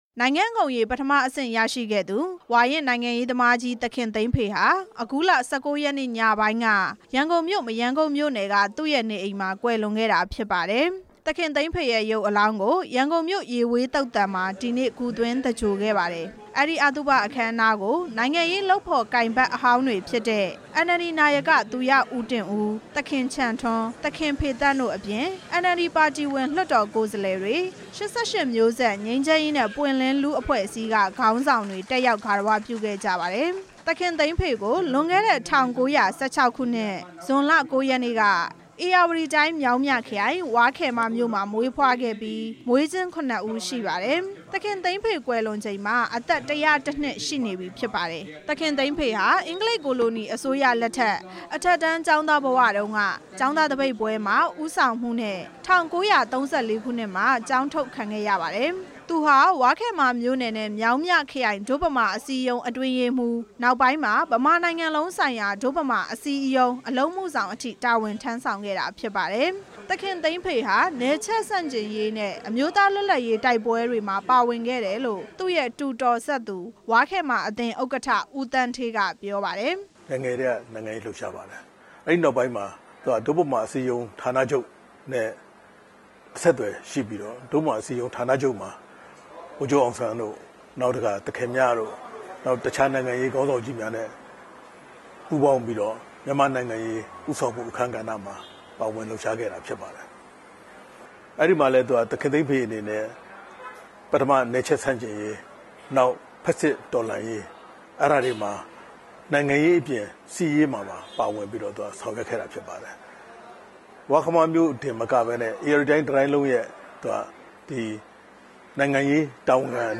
ဝါးခယ်မ သခင်သိန်းဖေရဲ့ ဈာပနအခမ်းအနား တင်ပြချက်